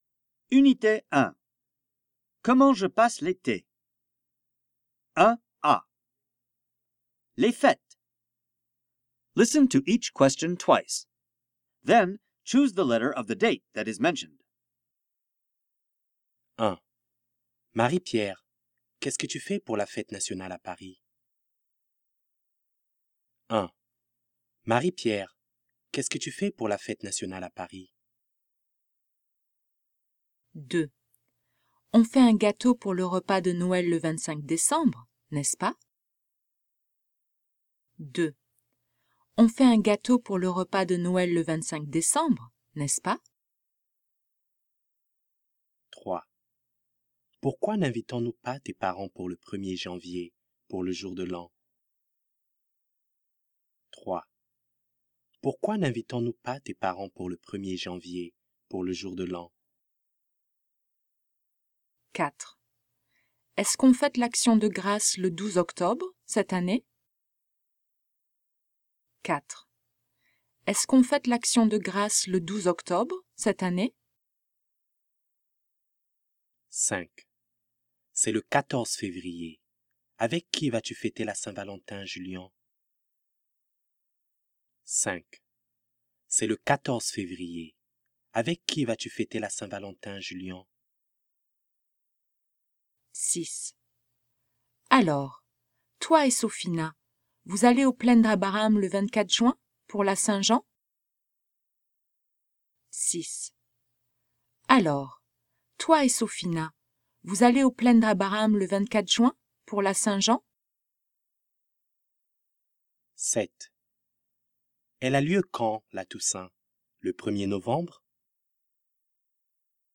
Listening Comprehension: Les fêtes!